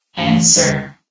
Yogstation/sound/vox_fem/answer.ogg
* AI VOX file updates